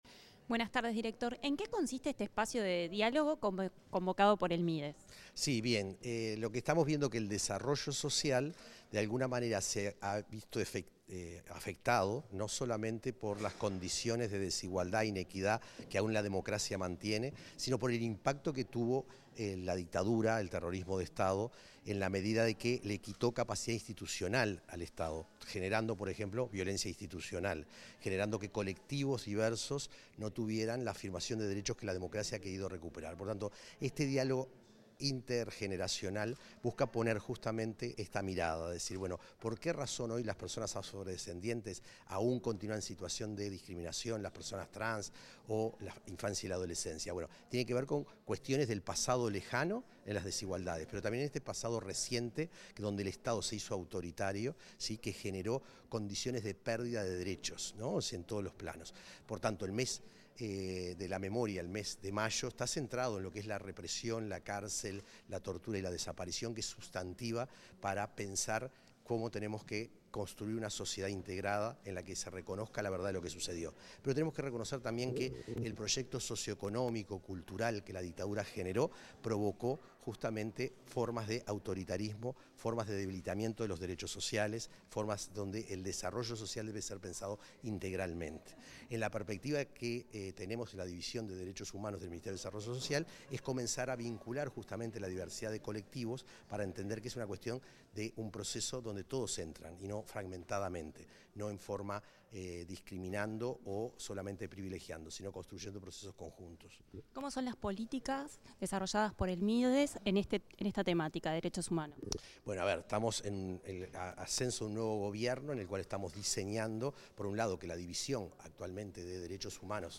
Entrevista a Nelson Villarreal
Entrevista al director de Derechos Humanos del Ministerio de Desarrollo Social, Nelson Villarreal, antes de su participación en un espacio de diálogo